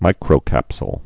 (mīkrō-kăpsəl, -sl)